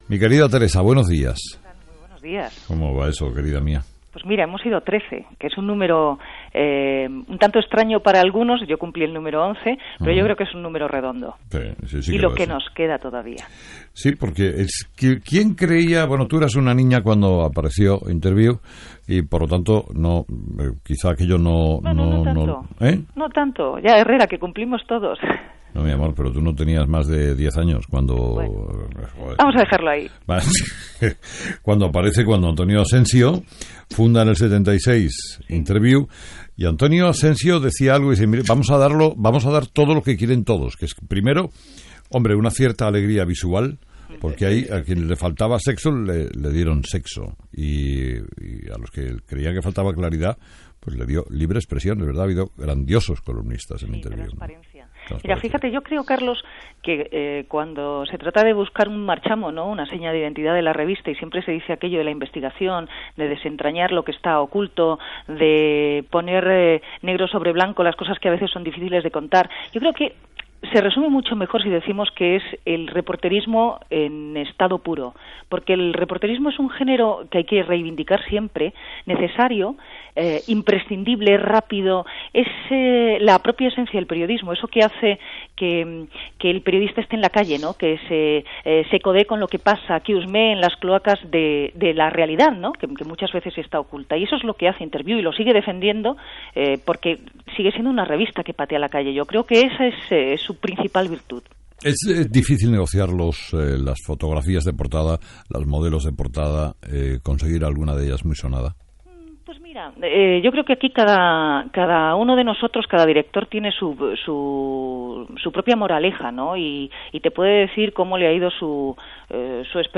Entrevista a Teresa Viejo
La periodista Teresa Viejo, una de las 13 personas que ha dirigido la revista Interviú, habla en Herrera en la onda de los 2.000 números de la revista.